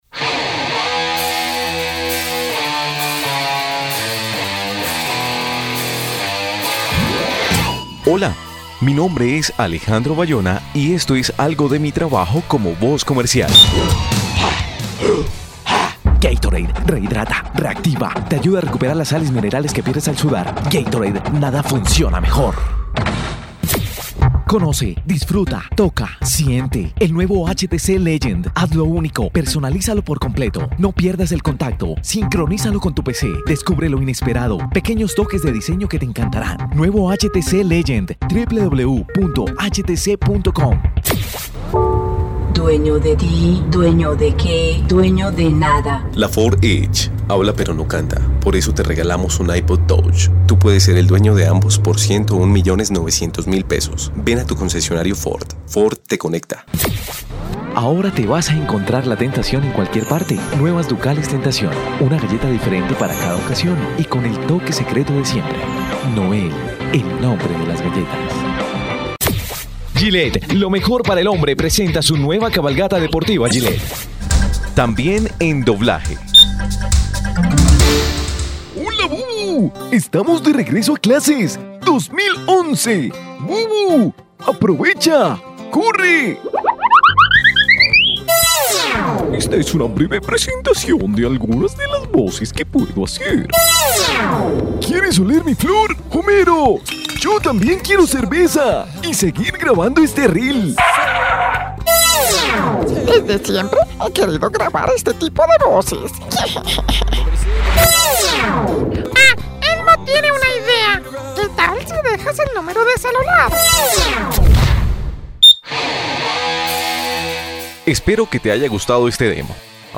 Sprechprobe: Werbung (Muttersprache):
Voz en Off, Voice Over Latino, Juvenil, enérgico y creible